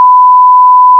Nella ricostruzione si ottiene un tipico andamento a scalinata come si mostra in fig.3. Facendo click sul grafico si ottiene il relativo suono.
Fig.3 Onda sinusoidale di ampiezza 1V e frequenza 1KHz campionata a 11KHz con campioni a 8 bit in formato mono.